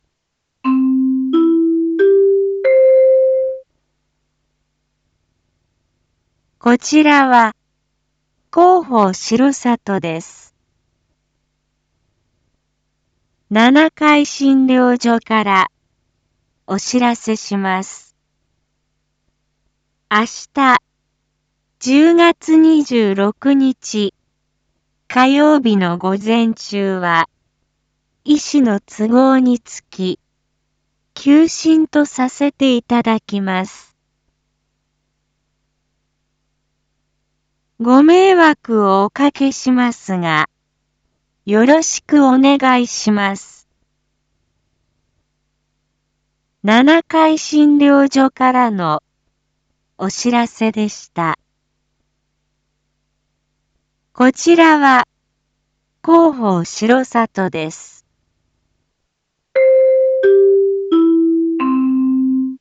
一般放送情報
Back Home 一般放送情報 音声放送 再生 一般放送情報 登録日時：2021-10-25 07:01:04 タイトル：R3.10.25 7時 インフォメーション：こちらは広報しろさとです 七会診療所からお知らせします 明日１０月２６日火曜日の午前中は、医師の都合につき、休診とさせていただきます。